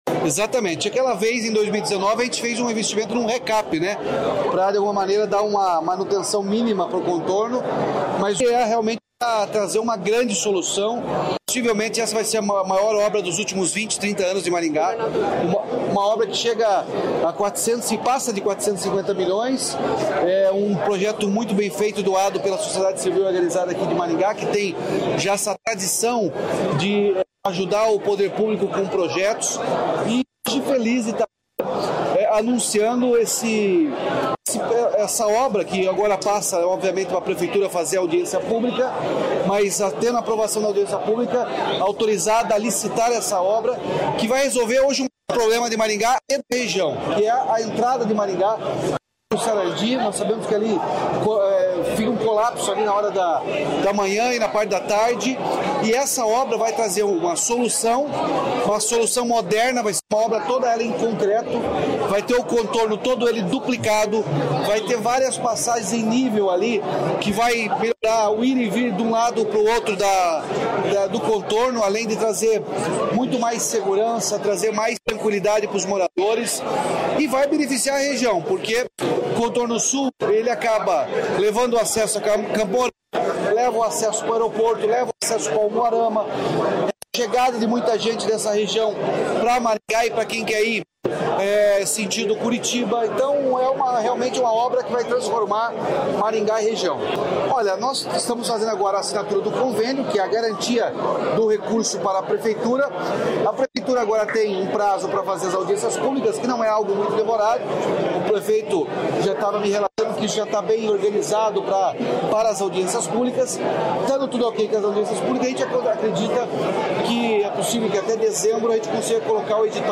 Sonora do governador Ratinho Junior sobre convênio da duplicação do Contorno Sul de Maringá